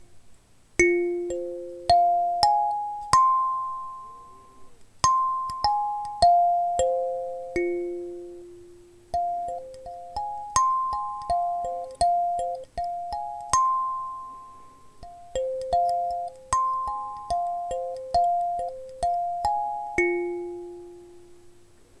Seven chrome-plated steel keys tuned to specific notes deliver a light sound that is pleasant for a recreational or classroom setting. The solid wood body projects the notes being played to deliver full and rich tones.